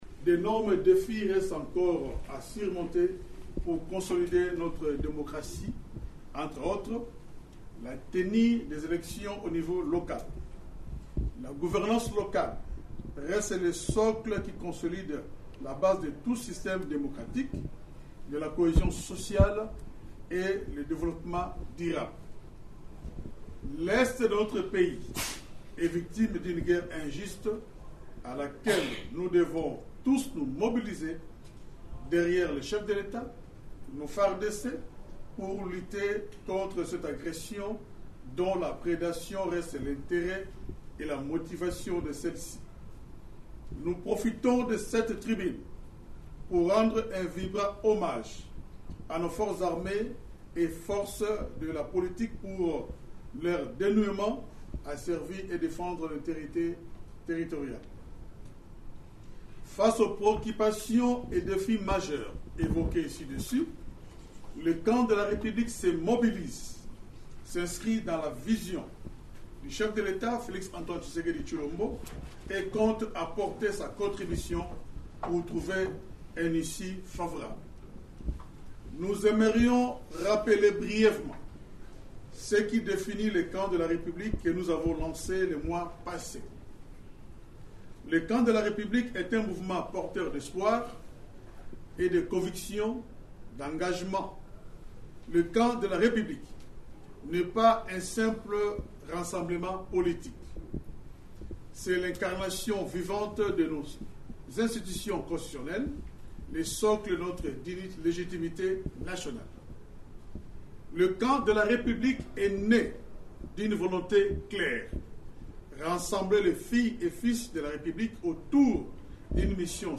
Le coordonnateur de cette plate-forme politique, Freddy Kita a livré sa cette information, lundi 1er septembre, lors d’une conférence de presse, tenue à Kinshasa.